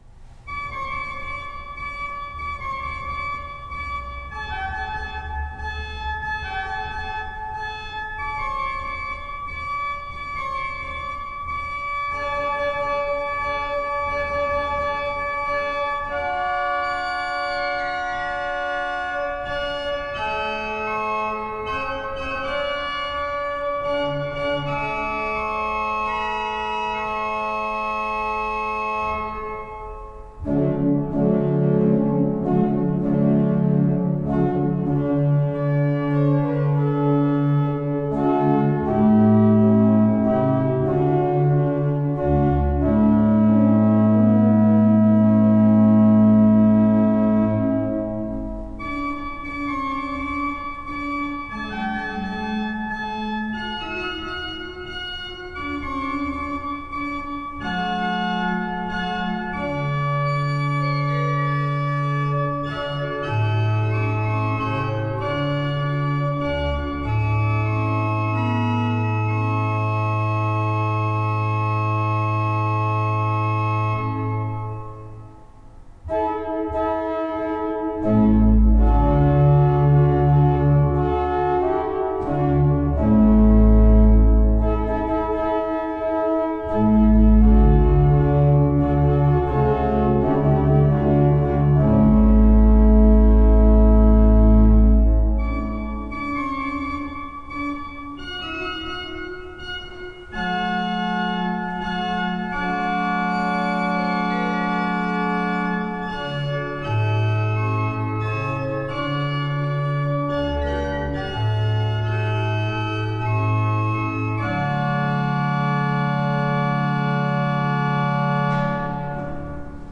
Orgel
Hier sind einige Hörproben unserer Orgel: